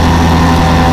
sound / acf_engines / i12l.wav